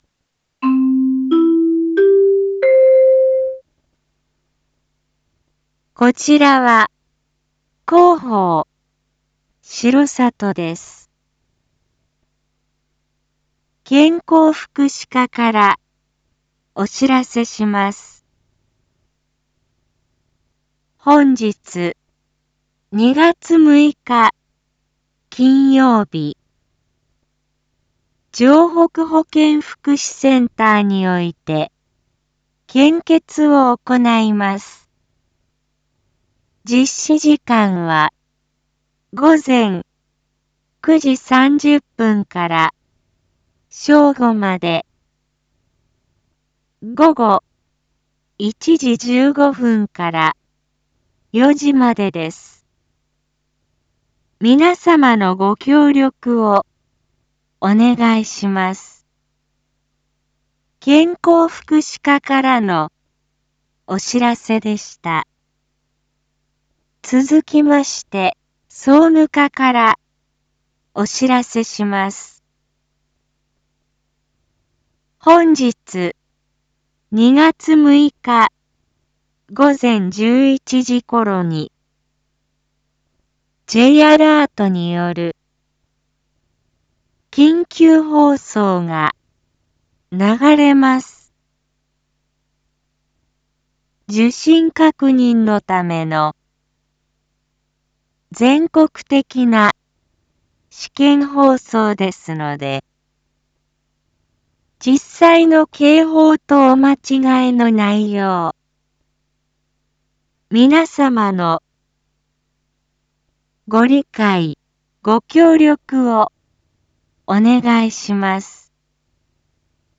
一般放送情報
Back Home 一般放送情報 音声放送 再生 一般放送情報 登録日時：2026-02-06 07:02:27 タイトル：献血（当日） インフォメーション：こちらは、広報しろさとです。